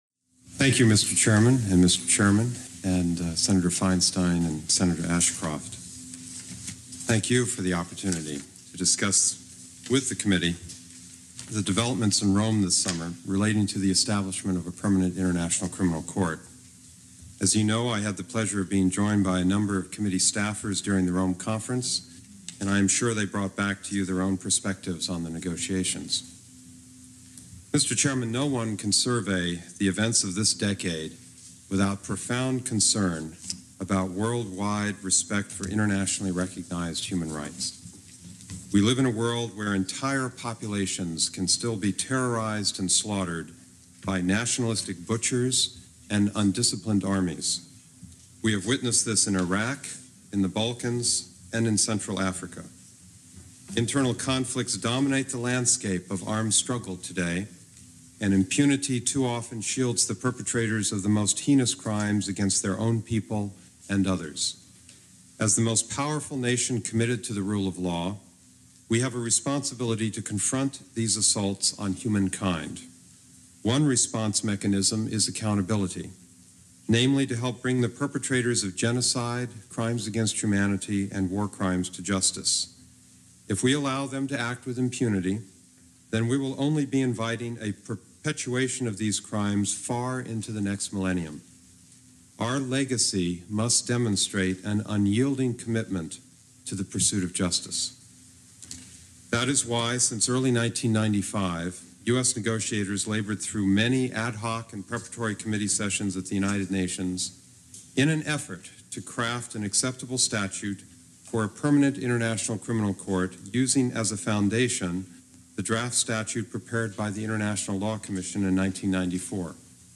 David J. Scheffer Opening Statement to the Senate Foreign Relations Committee on Post Rome Conference Creation of an International Criminal Court (transcript-audio-video)